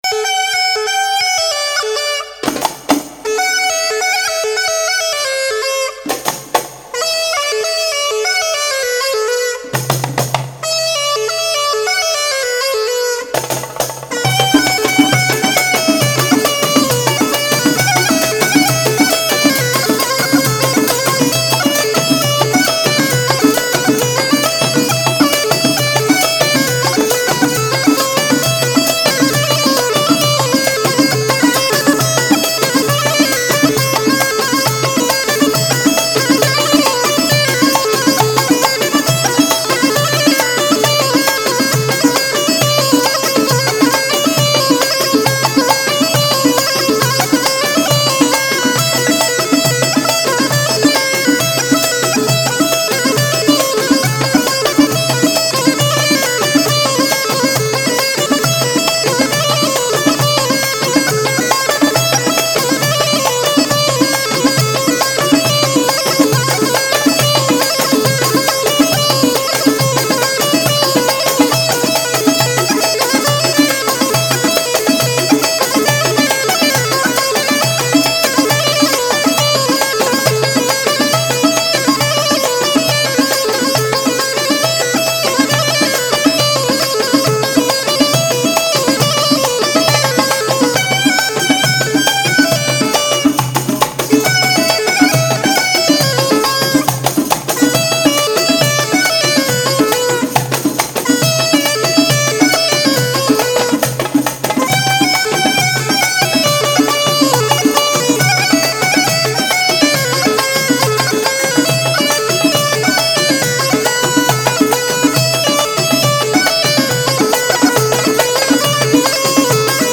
Bikalaam